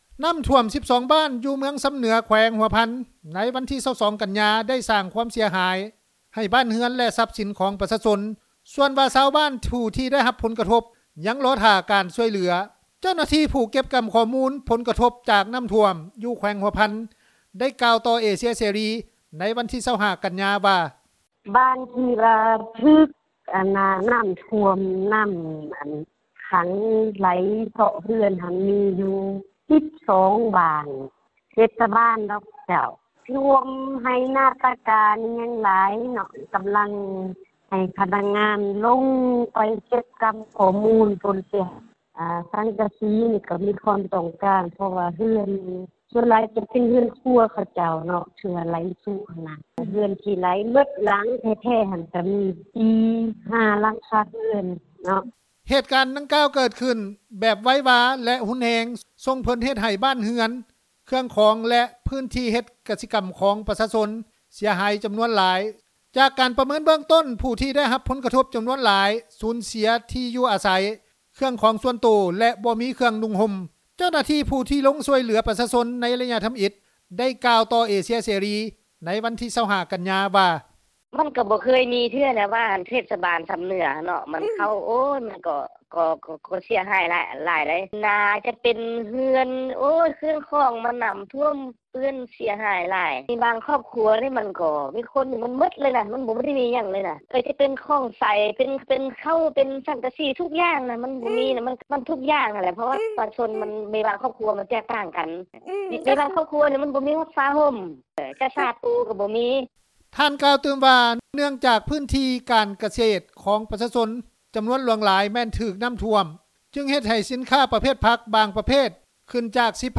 ເຈົ້າໜ້າທີ່ ຜູ້ທີ່ລົງຊ່ວຍເຫຼືອປະຊາຊົນໃນໄລຍະທໍາອິດ ໄດ້ກ່າວວ່າຕໍ່ເອເຊັຽເສຣີ ໃນວັນທີ 25 ກັນຍາ ວ່າ:
ຊາວບ້ານ ທີ່ໄດ້ຮັບຜົນກະທົບ ຢູ່ບ້ານນາລີວ ອີກຄົນນຶ່ງ ໄດ້ກ່າວວ່າ: